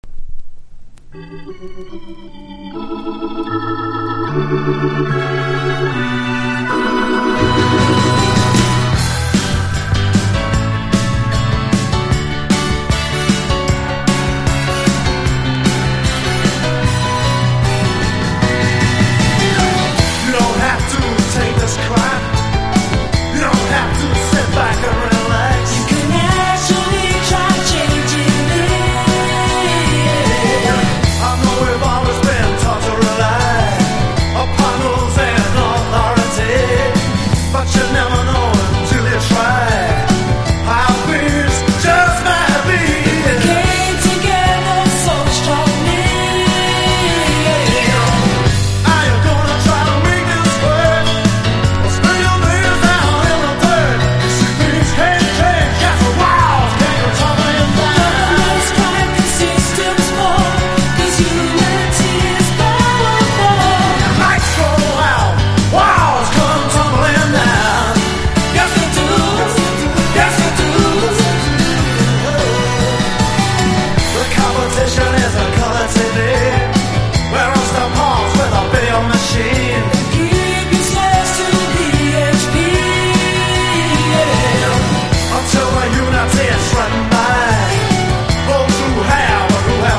# 80’s ROCK / POPS